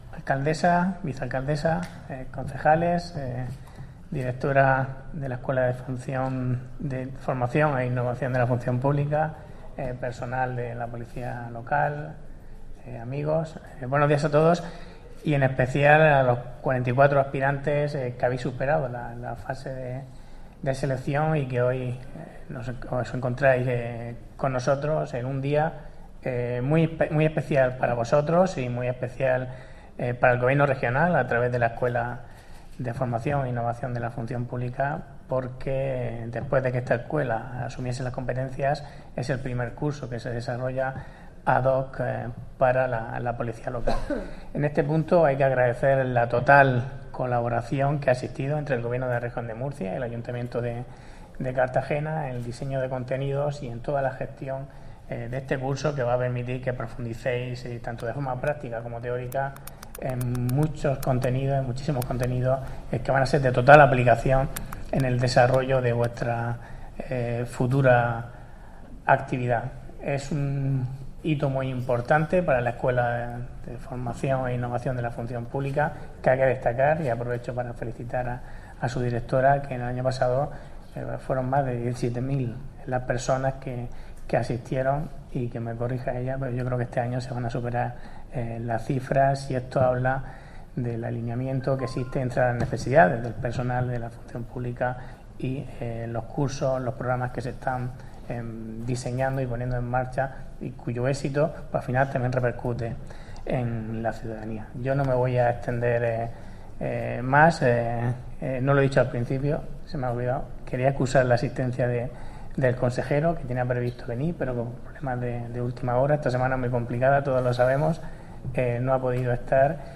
Audio: Declaraciones de la alcaldesa de Cartagena, Ana Bel�n Castej�n, tras el acto de ingreso de los agentes en pr�cticas de la Polic�a Local (MP3 - 2,83 MB)